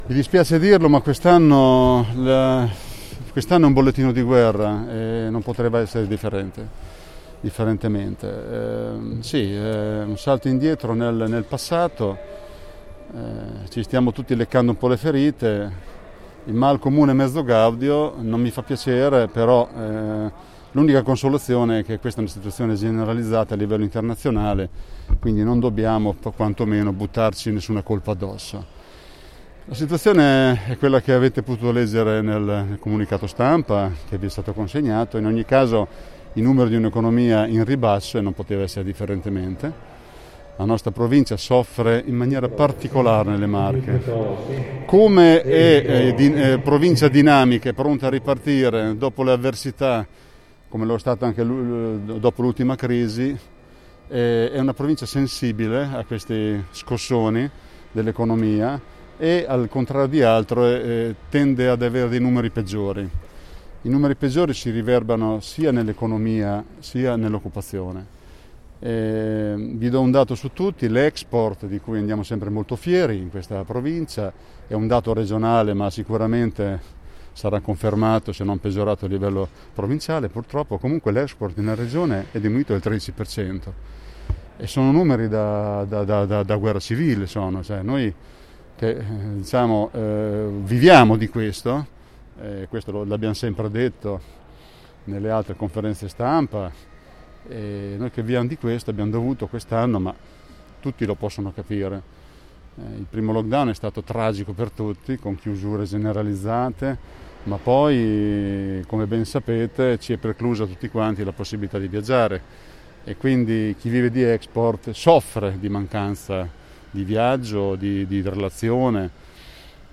Illustrati questa mattina nel corso della Conferenza stampa annuale della CNA di Pesaro e Urbino, tenutasi al Cantiere Navale Rossini, i dati economici relativi alle imprese che ci restituiscono un quadro sconfortante, anche se non completamente disastroso, considerati i due pesanti periodi di lockdown.